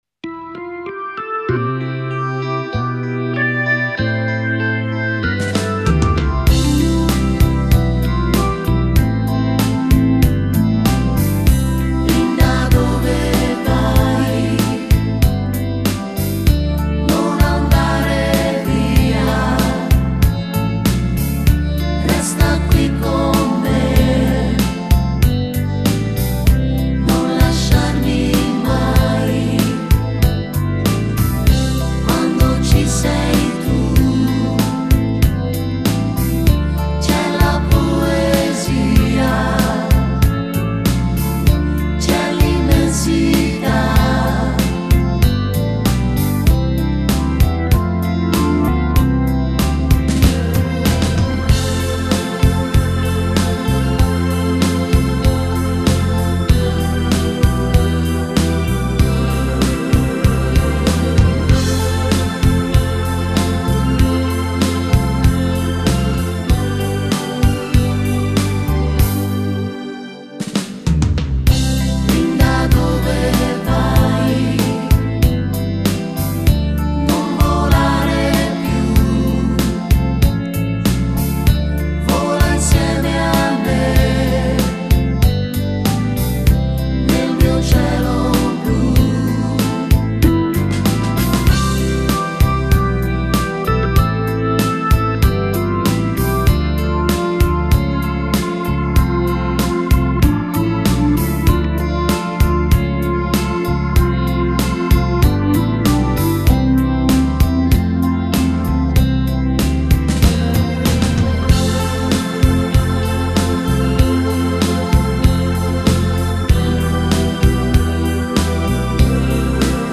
Genere: Lento
Scarica la Base Mp3 (2,68 MB)